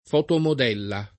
fotomodella [ f q tomod $ lla ] s. f.